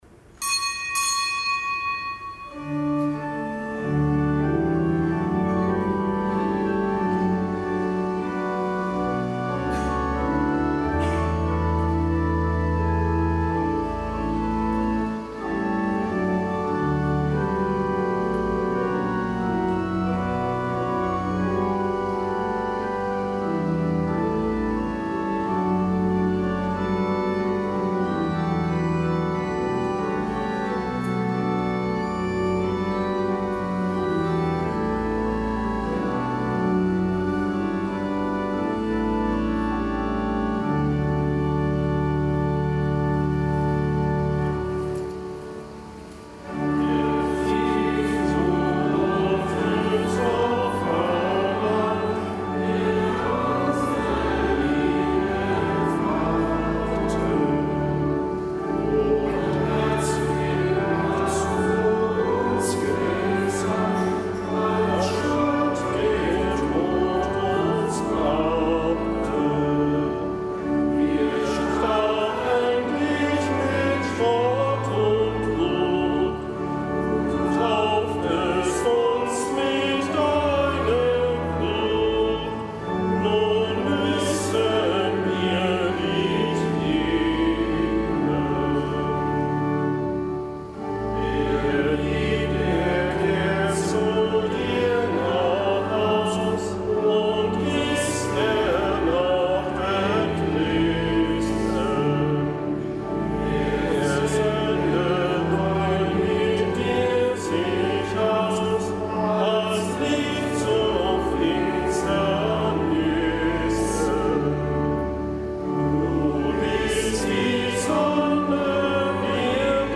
Kapitelsmesse aus dem Kölner Dom am Freitag der dreizehnten Woche im Jahreskreis.